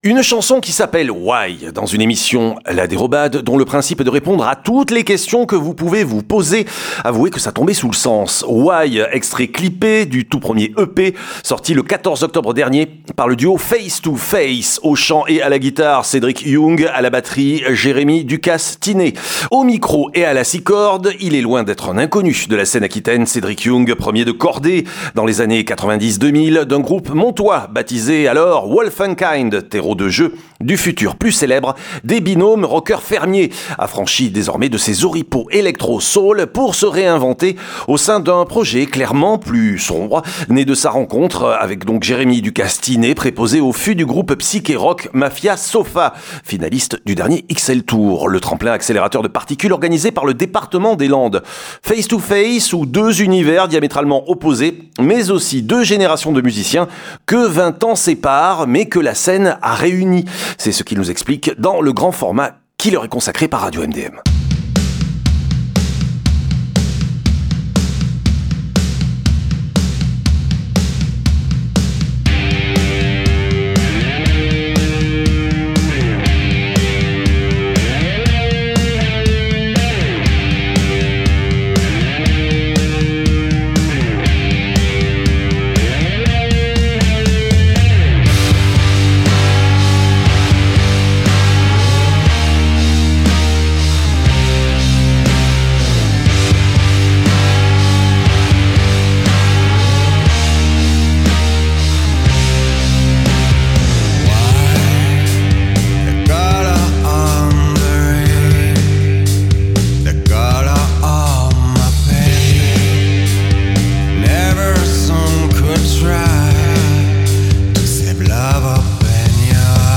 Le duo aquitain
guitare /chant